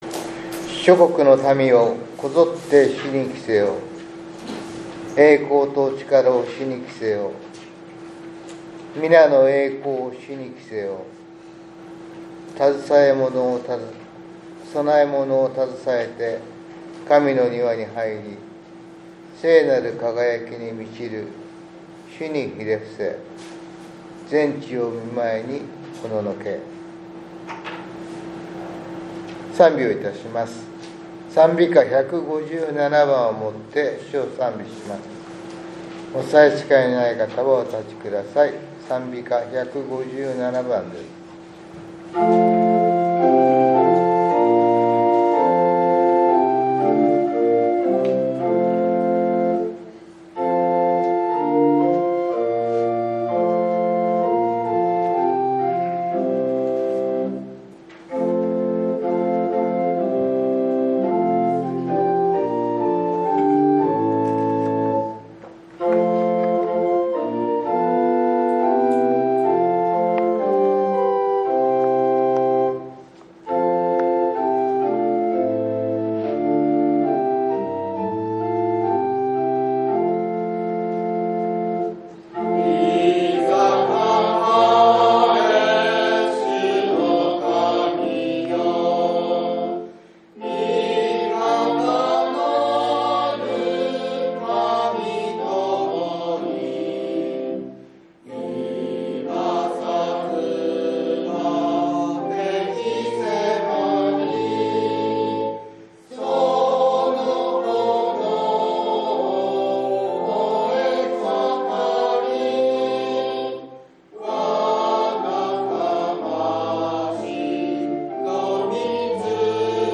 １０月２６日（日）主日礼拝